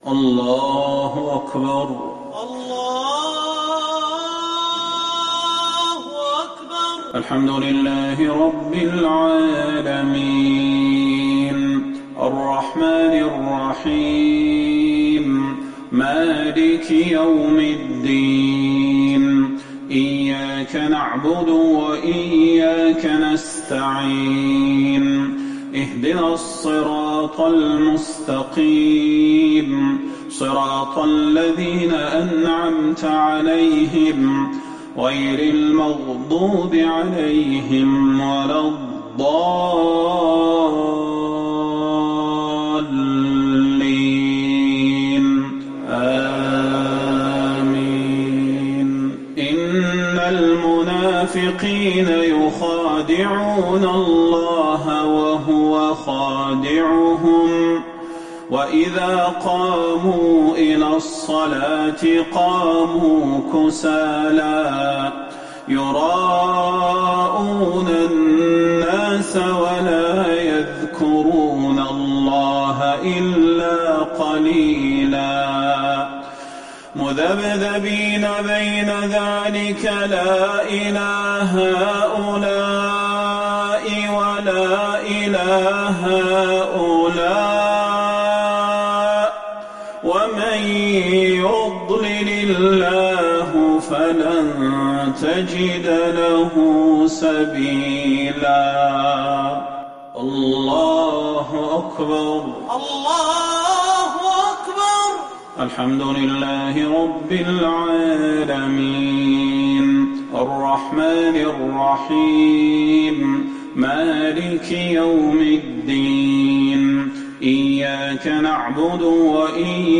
صلاة العشاء للقارئ صلاح البدير 7 جمادي الآخر 1441 هـ
تِلَاوَات الْحَرَمَيْن .